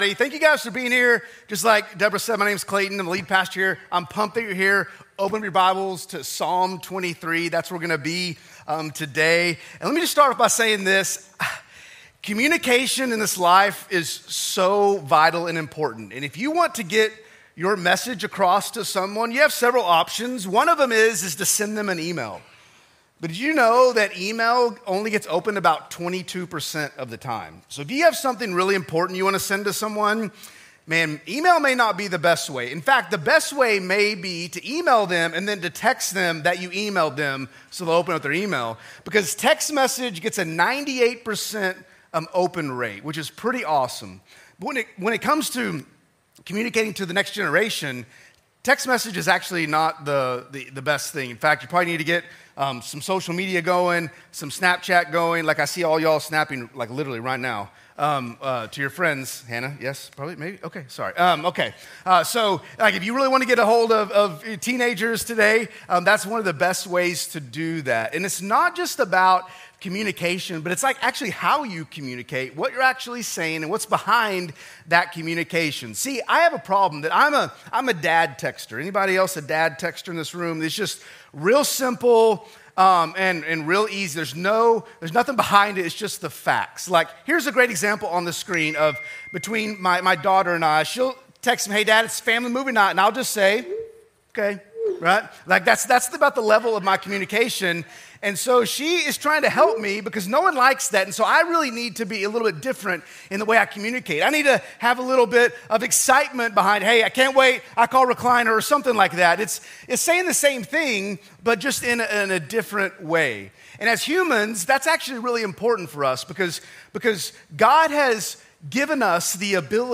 A message from the series "BEST SELLER."